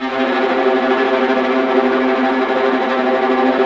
Index of /90_sSampleCDs/Roland LCDP08 Symphony Orchestra/STR_Vas Bow FX/STR_Vas Tremolo